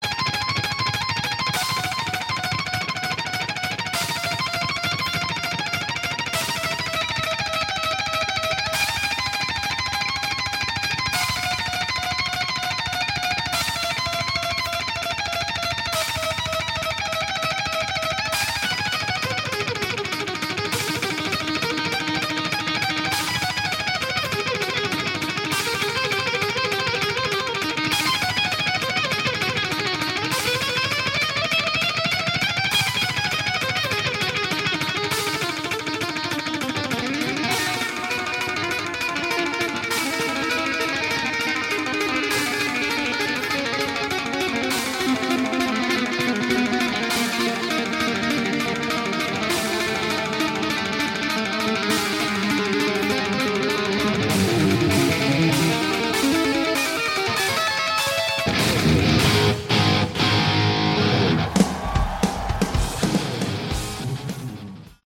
Category: Hard Rock
vocals
guitars
bass
drums
Recorded live in 2008